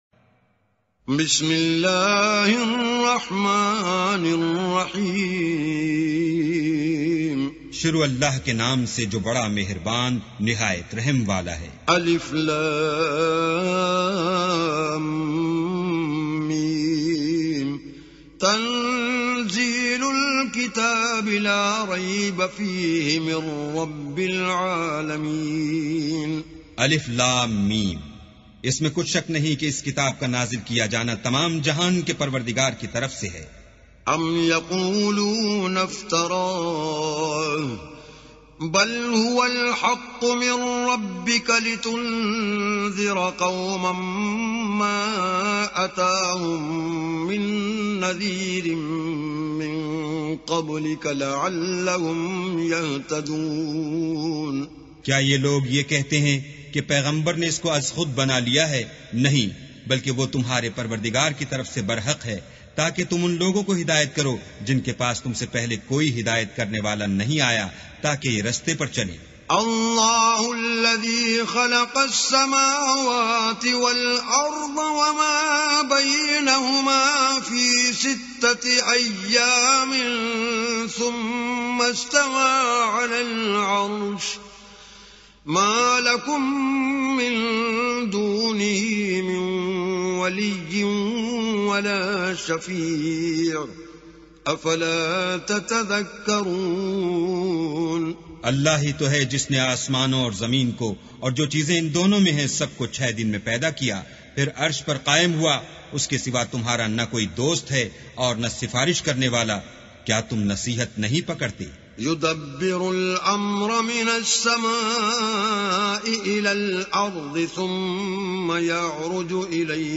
Quran Tilawat / Recitation